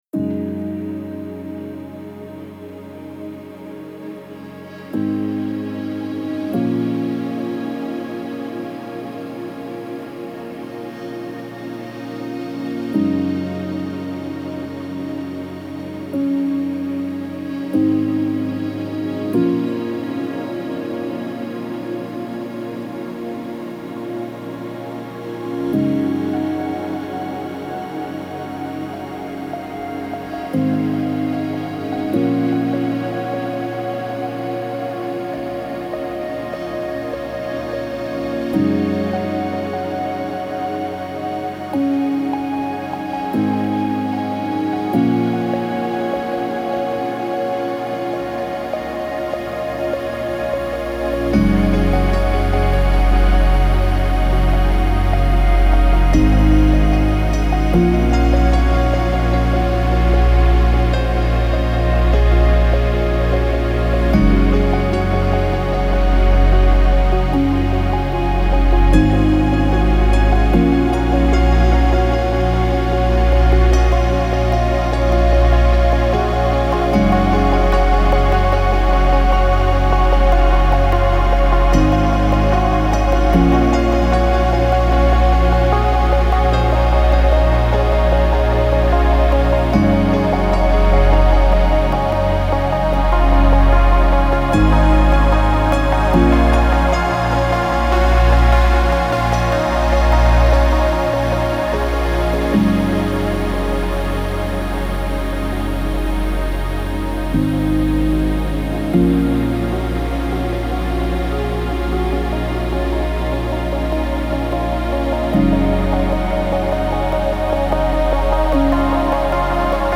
موسیقی بی کلام امبینت